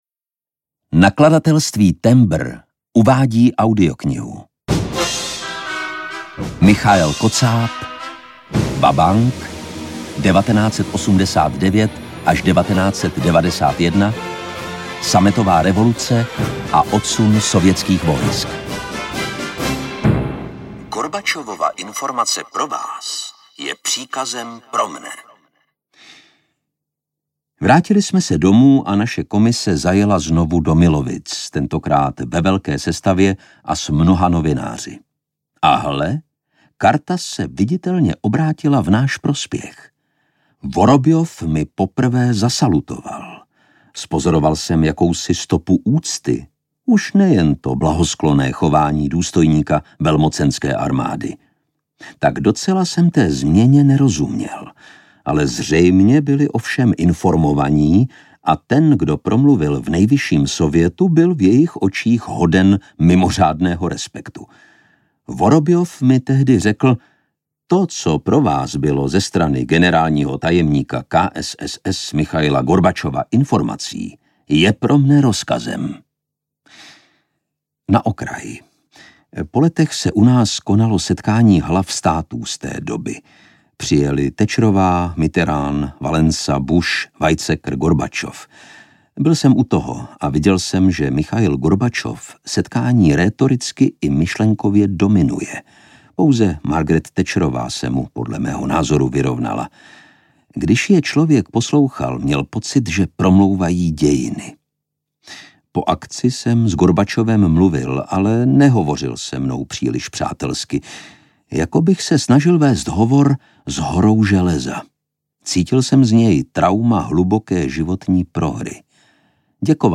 Vabank audiokniha
Ukázka z knihy